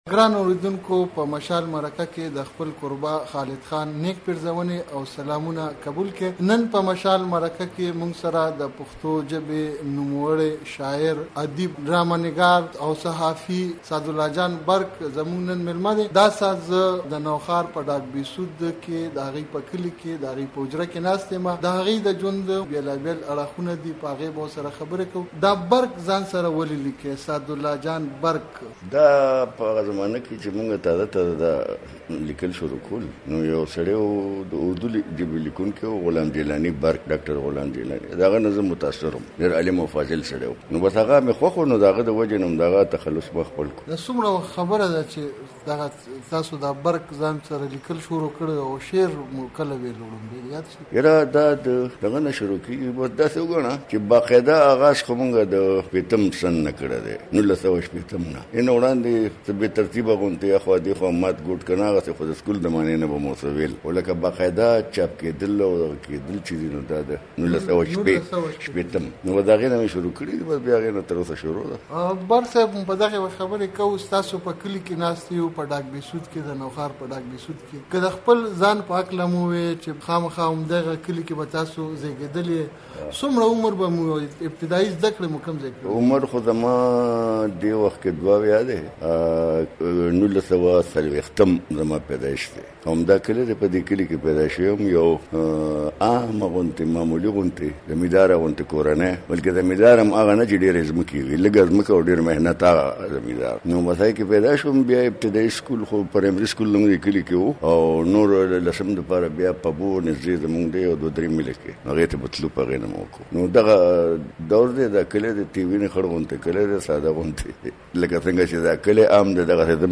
سعدالله جان برق دمشال سره په مرکه کې